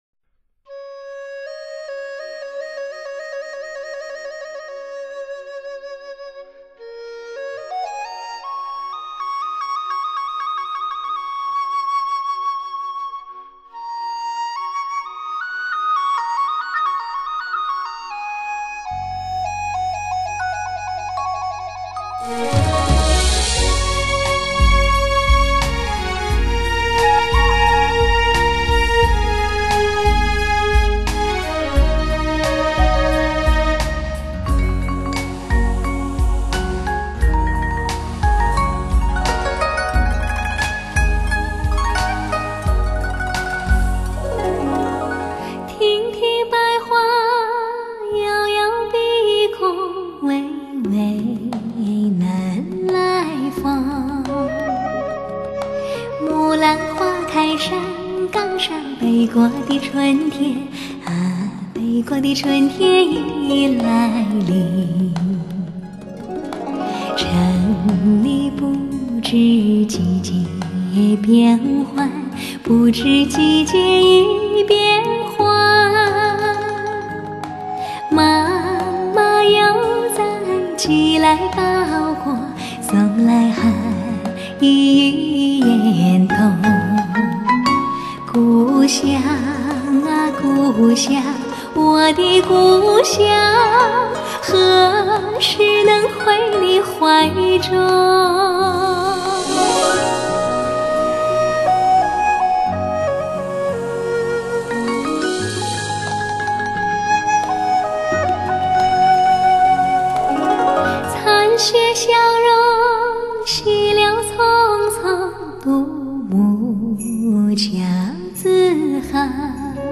日本民歌